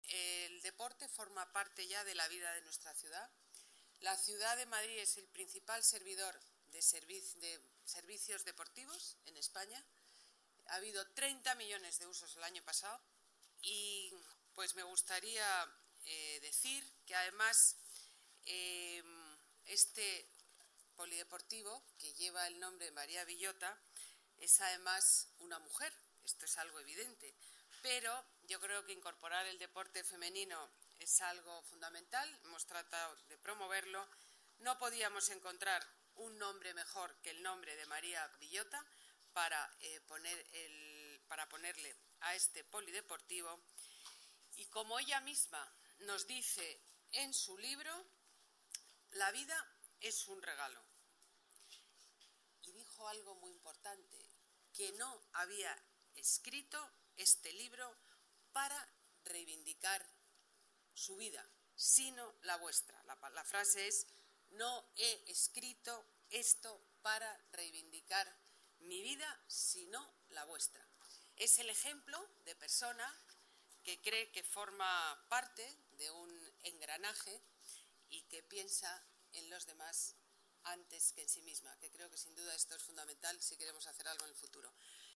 Nueva ventana:Declaraciones de Ana Botella, alcaldesa en funciones, en la inauguración de la instalación deportiva María de Villota